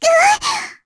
Lilia-Vox_Damage_kr_02.wav